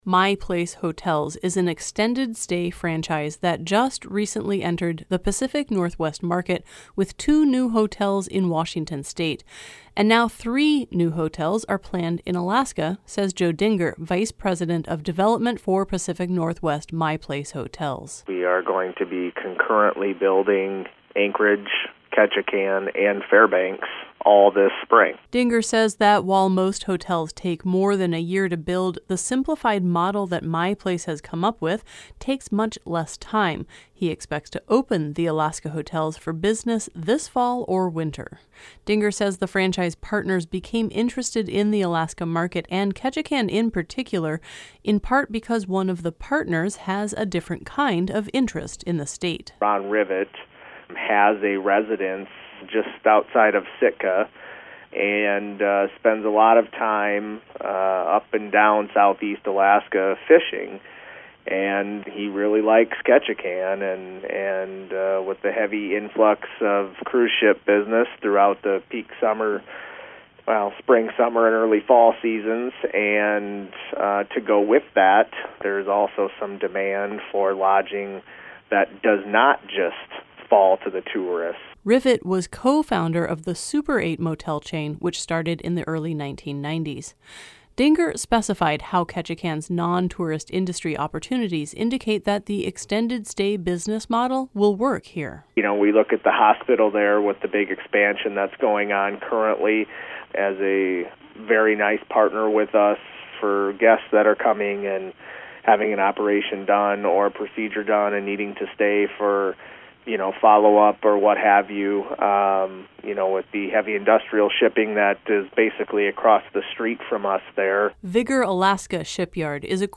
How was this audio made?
“We are going to be concurrently going to be building Anchorage, Ketchikan and Fairbanks, all this spring,” he said in a telephone interview last week.